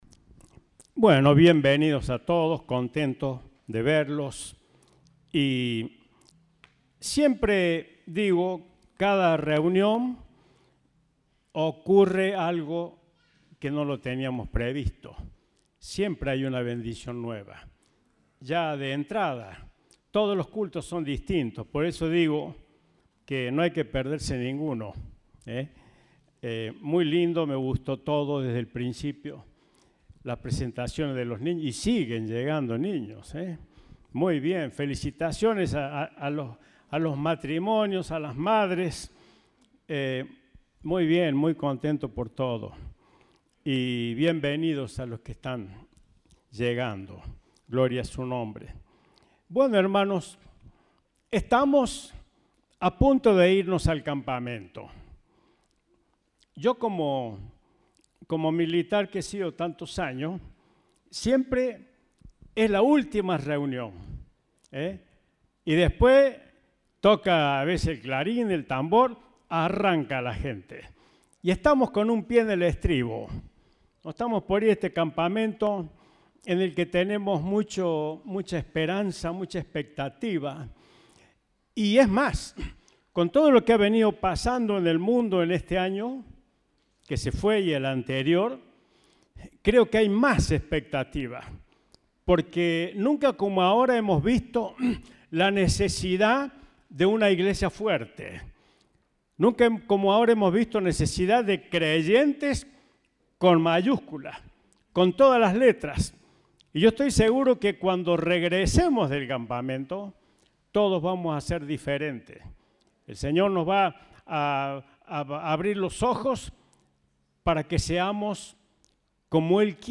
Compartimos el mensaje del Domingo 20 de Febrero de 2022.